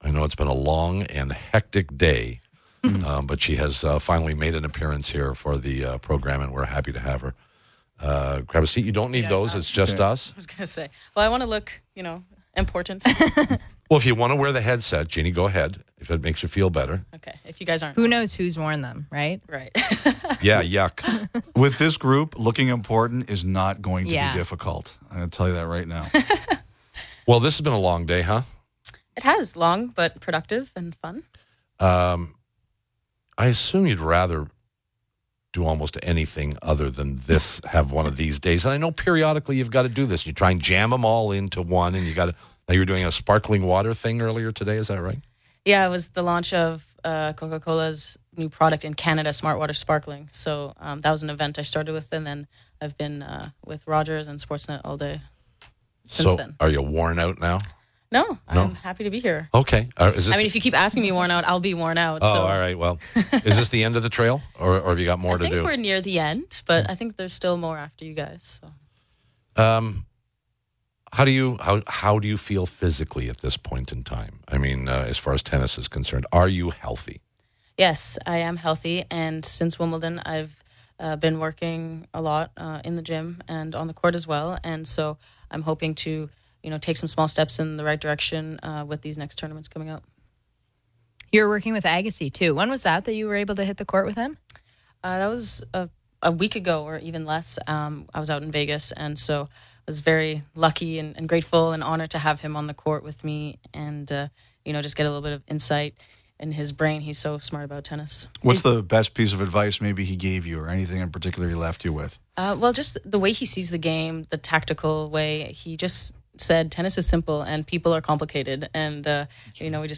Bouchard also spoke about some of her recent struggles, the state of women’s tennis right now, and the advice and inspiration she’s gained from working with tennis legend Andre Agassi. Listen to the full interview here: Tennis star Genie Bouchard on PTS ahead of the Rogers Cup July 25 2017